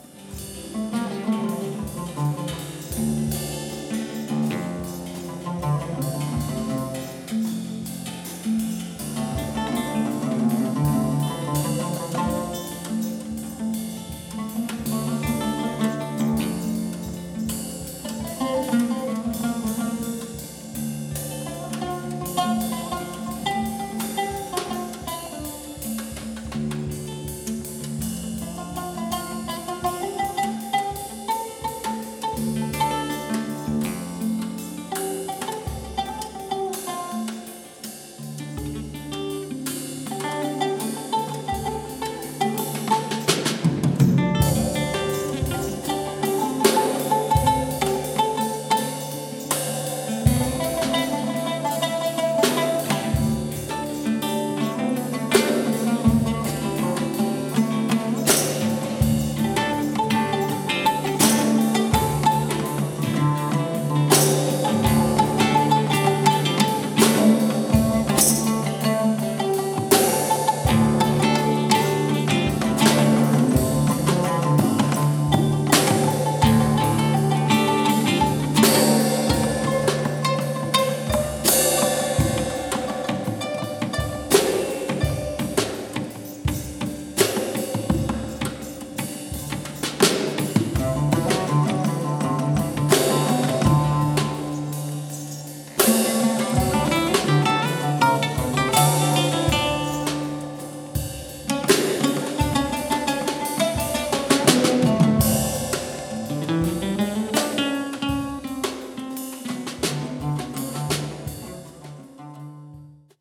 ütőhangszerek
klasszikus és jazz gitár
keleti húros hangszerek (török lant, sitar, dusar)
𝄞 Magyarkanizsai koncertfelvételek